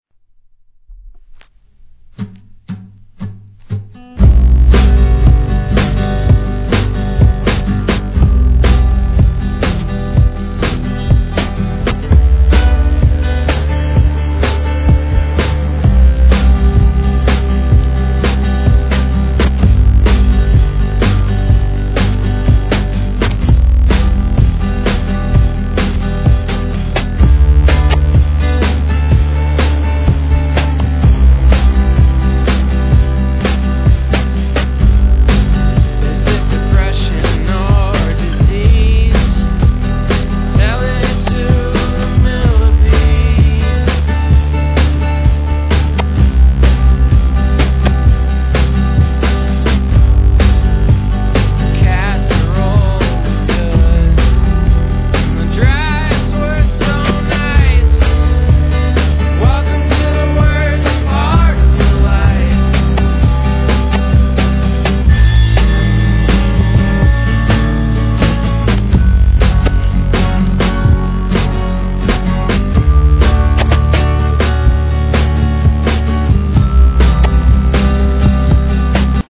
1. HIP HOP/R&B
フォーク、ブルース、ロックからヒップホップ・ブレイクと融合させるもはや垣根は逆さの世界。